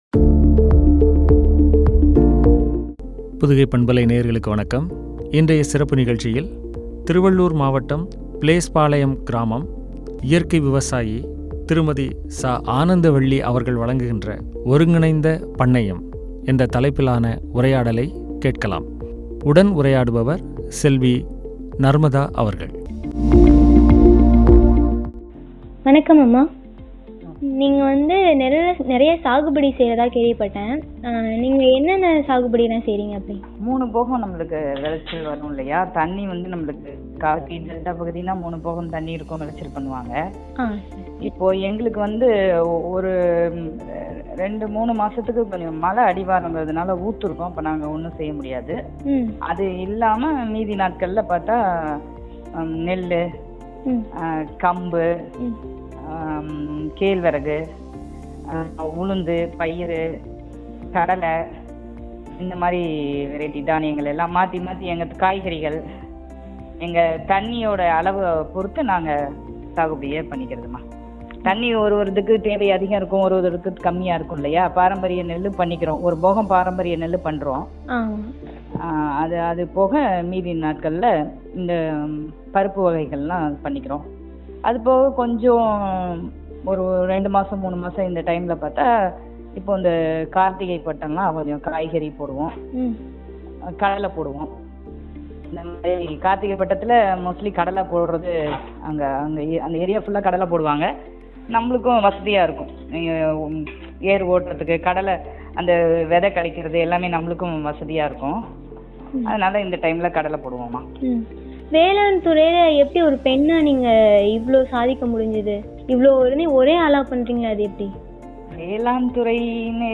ஒருங்கிணைந்த பண்ணையம் பற்றிய உரையாடல்.